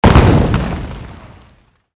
impact_explosion_01.mp3